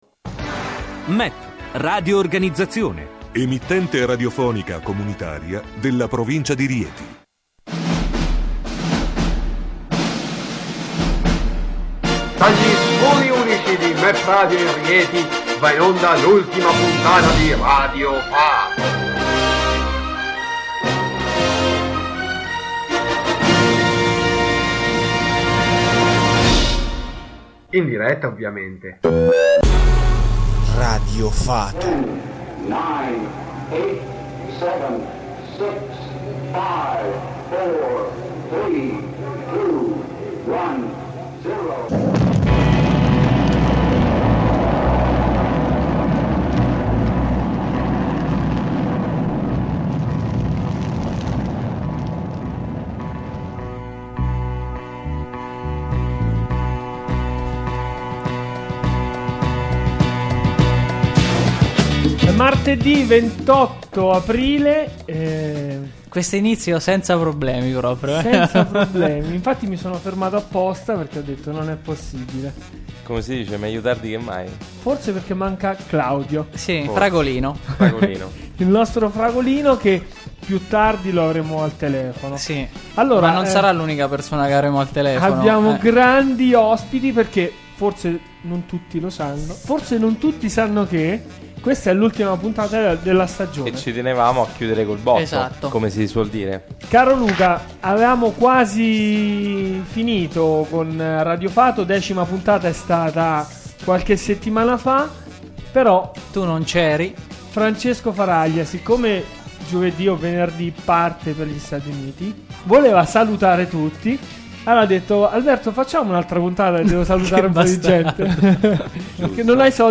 Tanta bella musica